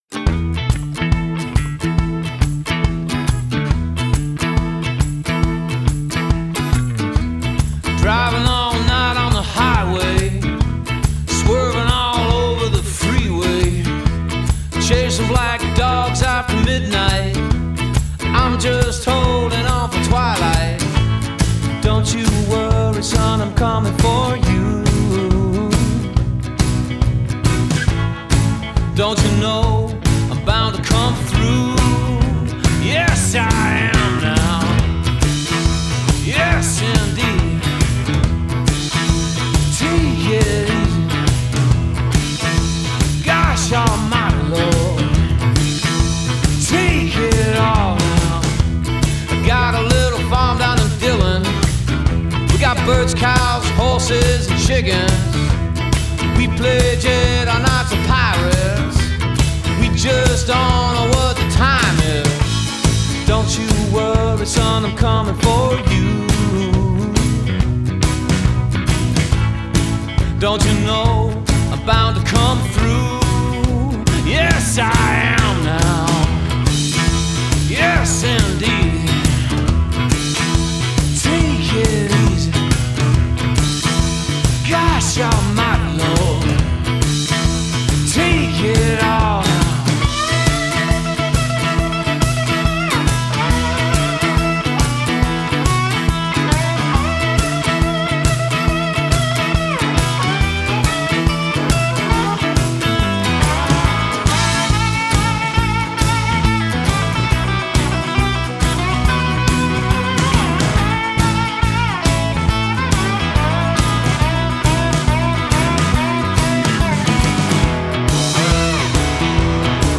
Bluesy-rock is the best way to describe it.
fun tune